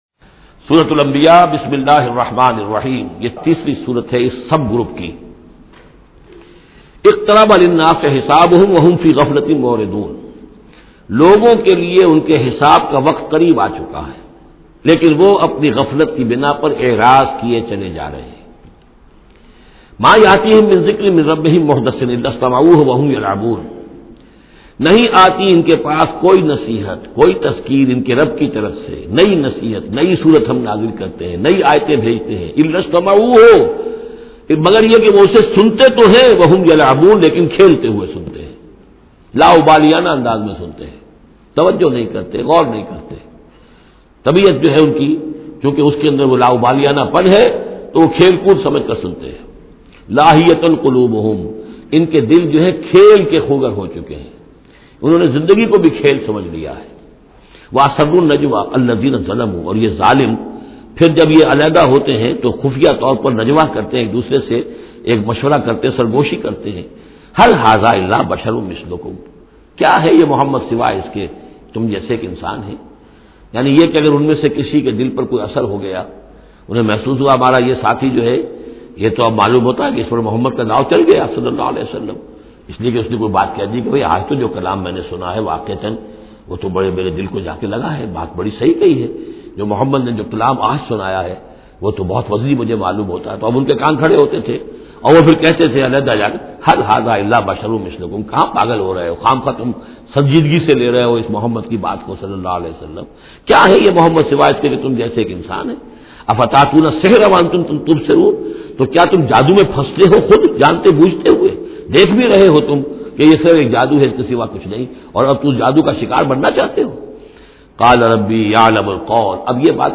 Listen online and download urdu tafseer of Surah Al Anbiya in the voice of Dr Israr Ahmed.